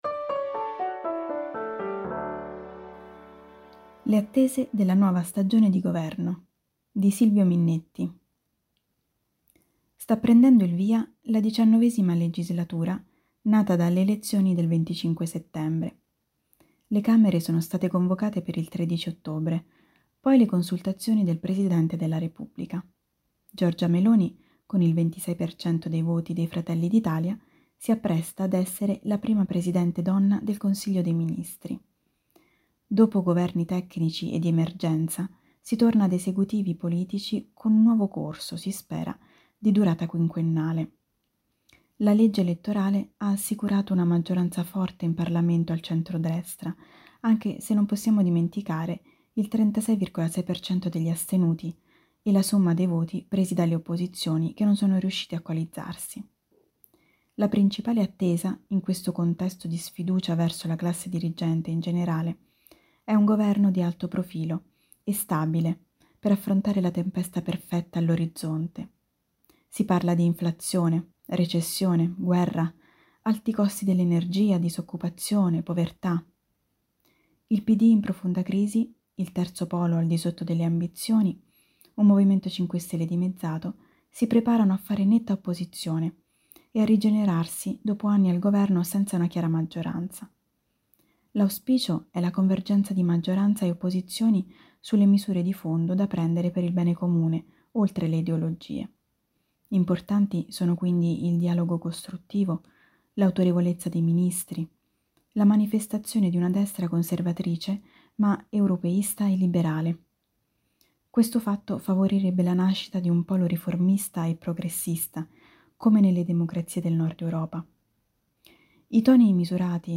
Al microfono, i nostri redattori e i nostri collaboratori.
Ecco i 10 articoli letti per voi dalla rivista di novembre.